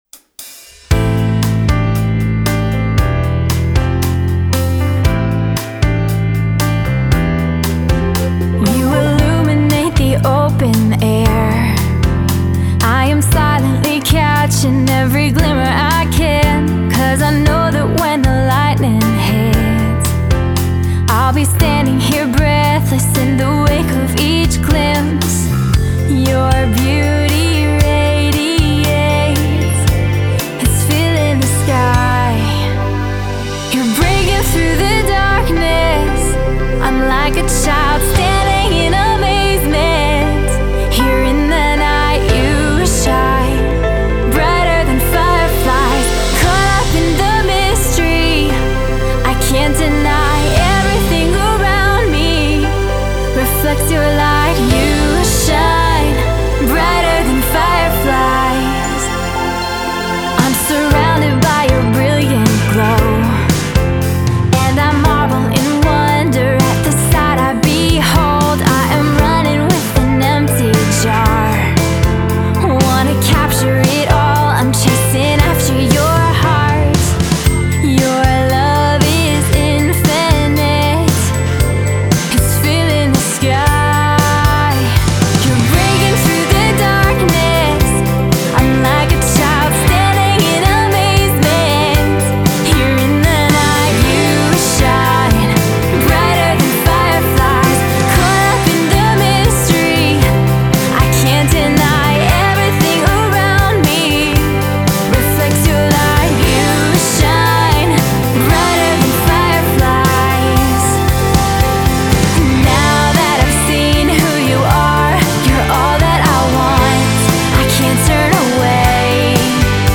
Genre: Christian/Indiepop/Piano Rock/Female Vocal